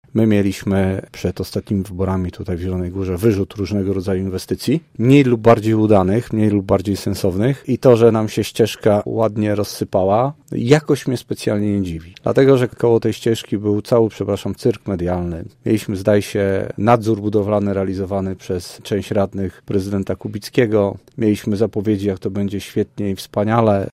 Radnego nie dziwi, że ścieżka się nadaje do poprawy. Gdyby nie realizacja inwestycji przed wyborami, byłaby możliwość ich dopilnowania – mówił przedstawiciel Lewicy: